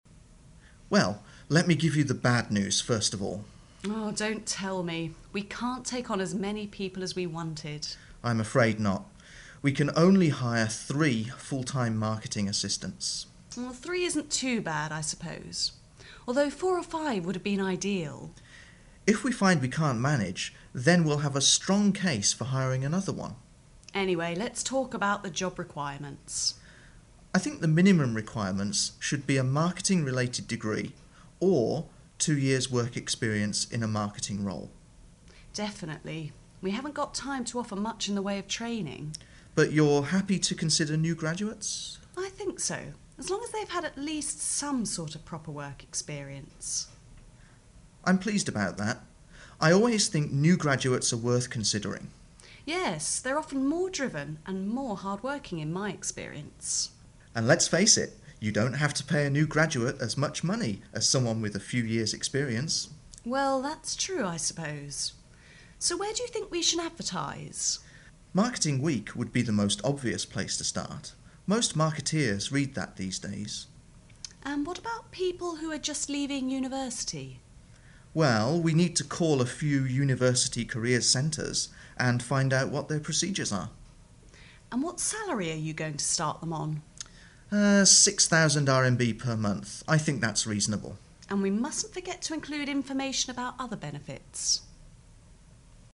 Listen to the dialogue between Head of Marketing (HM) and Marketing Manager (MM)